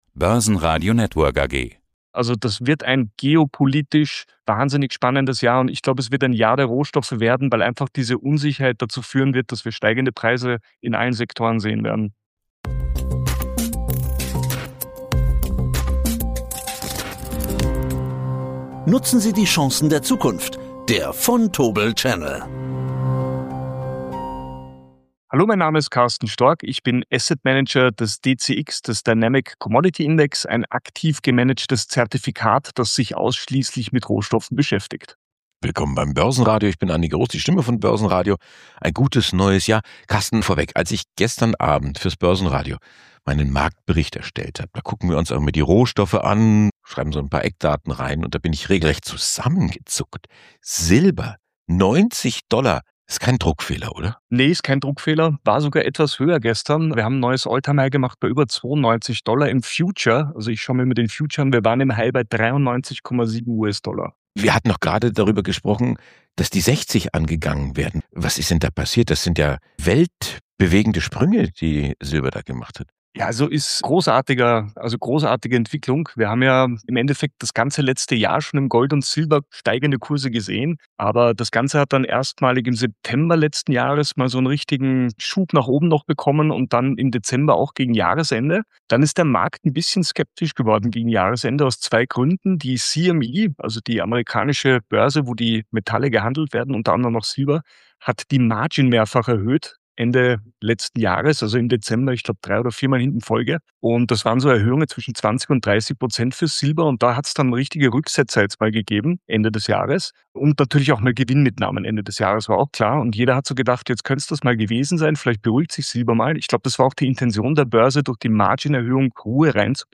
Ein Gespräch über Rohstoffe, Macht und Kapitalflüsse in einer nervösen Welt.